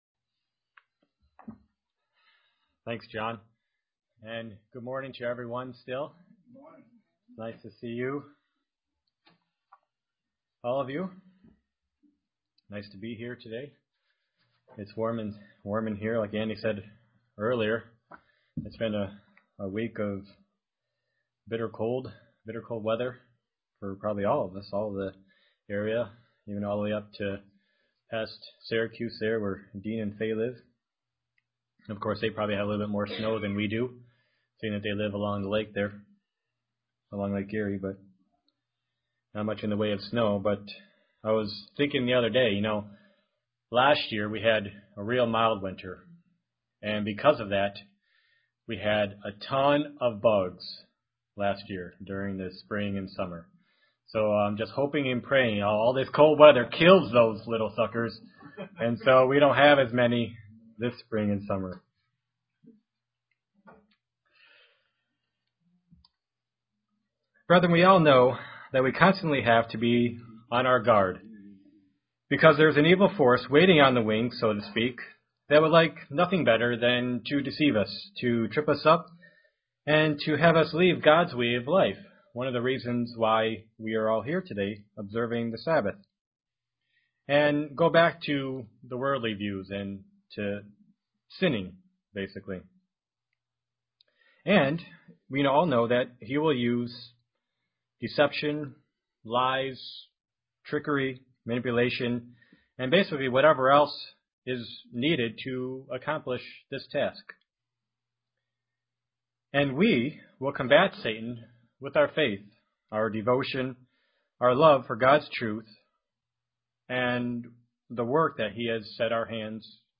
Print Five spiritual areas to combat apathy UCG Sermon Studying the bible?